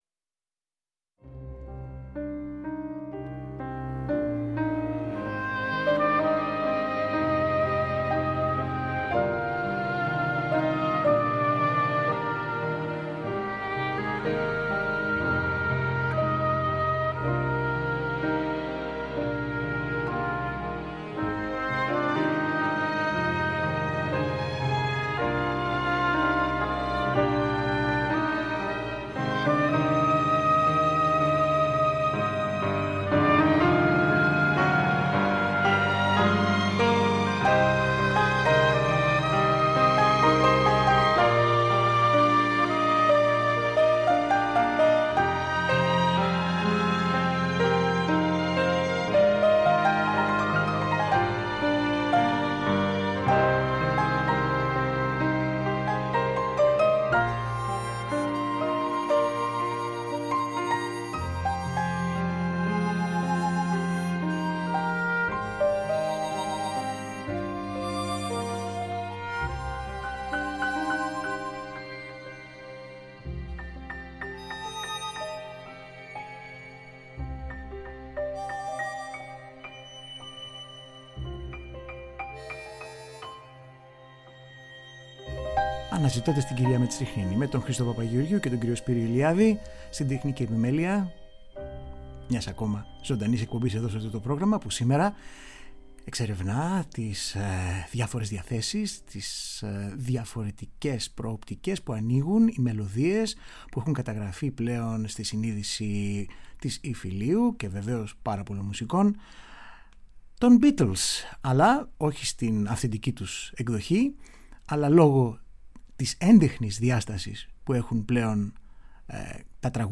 Τραγούδια και μελωδίες του δημοφιλούς συγκροτήματος που πέρασαν σε «κλασικότροπες» εκδοχές